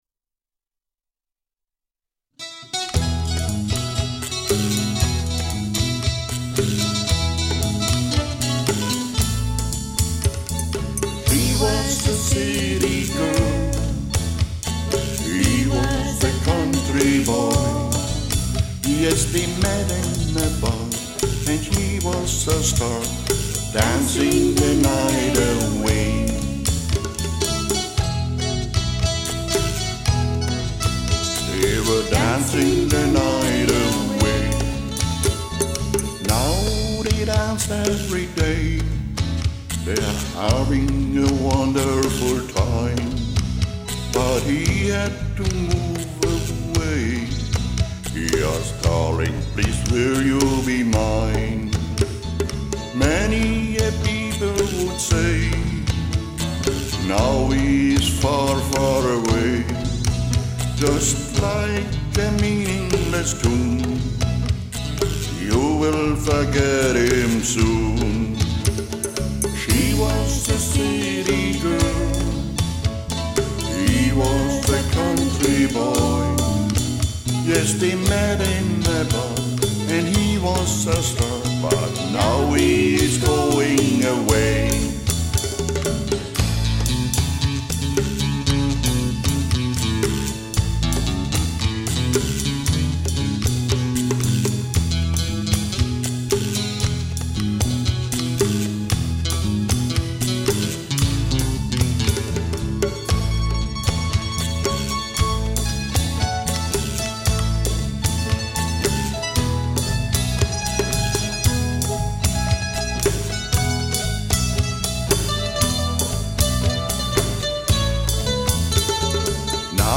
I can't exactly pick out this guy's accent.